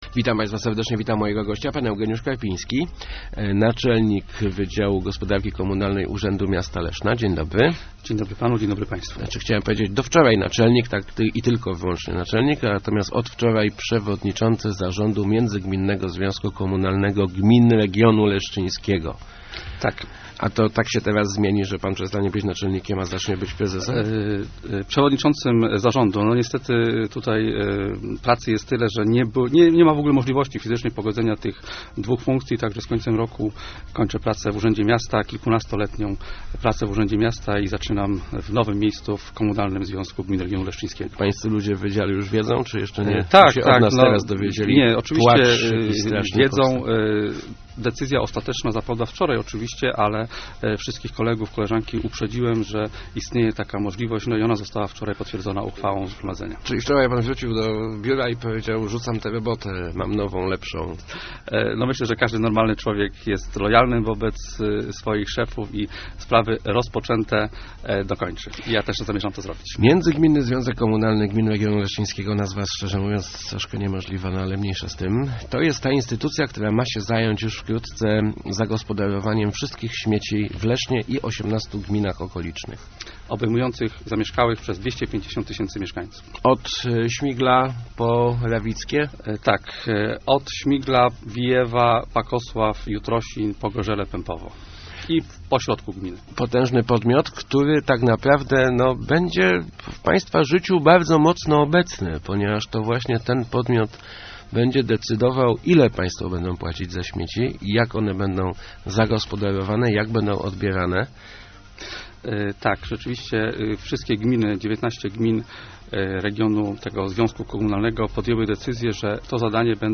Wysokość stawki opłaty za zagospodarowanie odpadów ustalimy w styczniu - mówił w Rozmowach Elki Eugeniusz Karpiński, przewodniczący zarządu Międzygminnego Związku Komunalnego Gmin Regionu Leszczyńskiego.